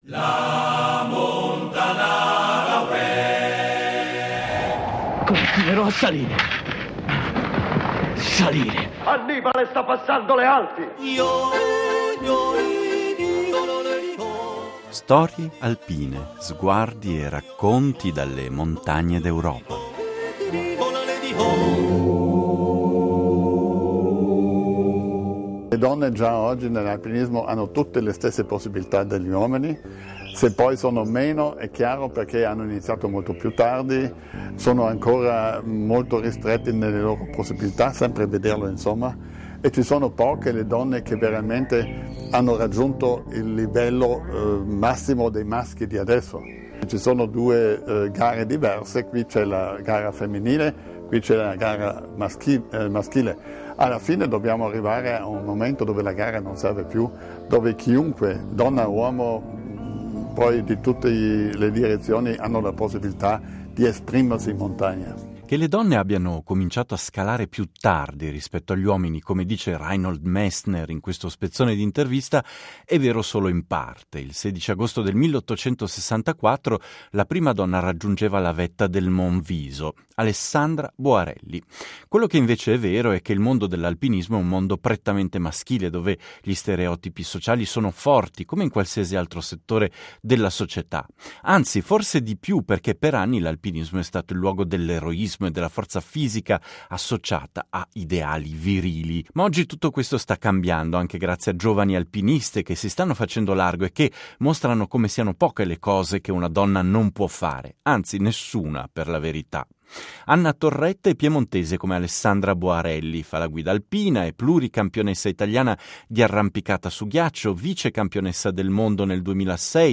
Riflessioni sulle Alpi raccolte al Film Festival della Montagna di Trento